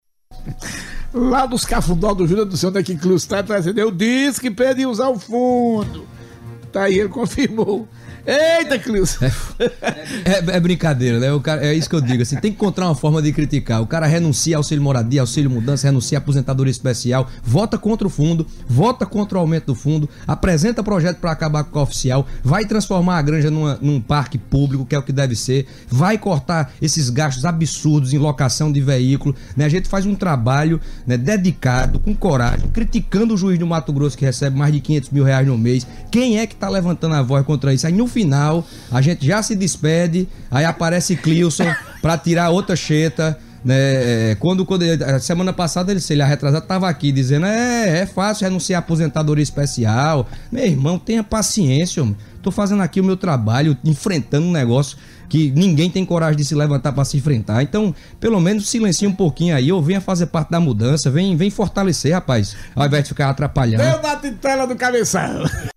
deputado-federal-Pedro-Cunha-Lima-PSDB.mp3